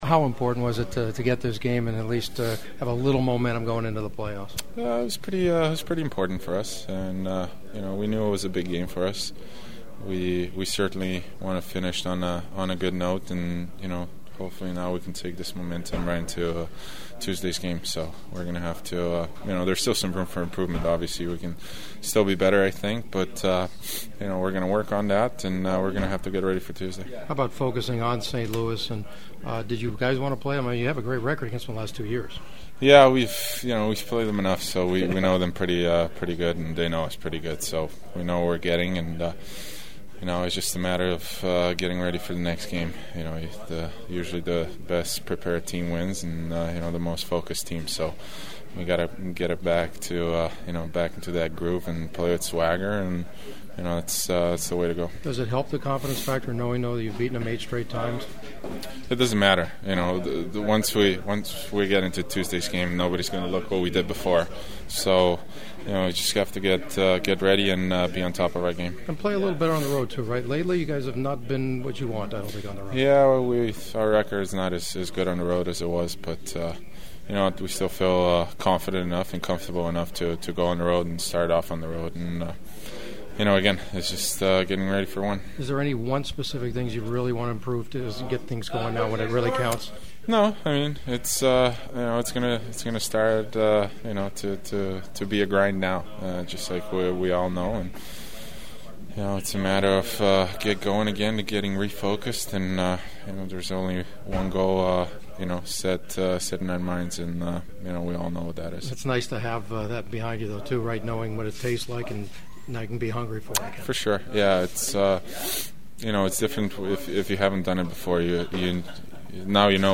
Center Anze Kopitar who finished the schedule with an assist on the first goal against the Sharks and the Kings leading scorer (for a 6th straight season) with 42 points (10 goals-32 assists) talked about the importance of building some momentum into the real season…